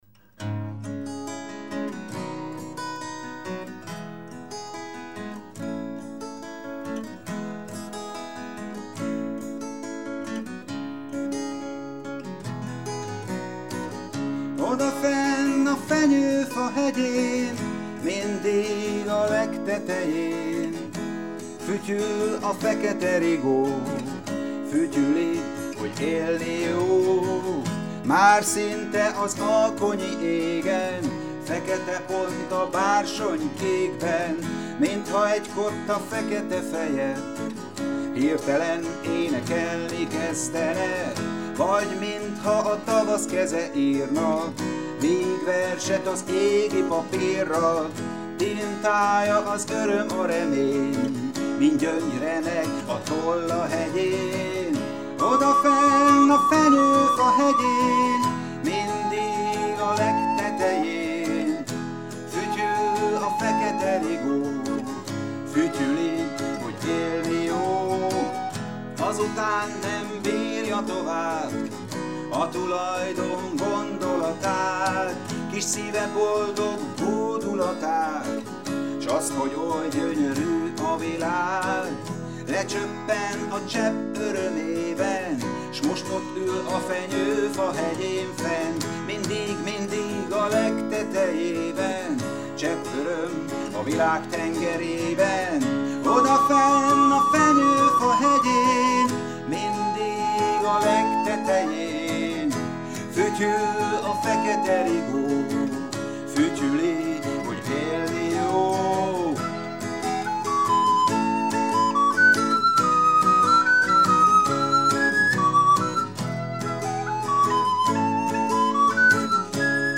furulya-nek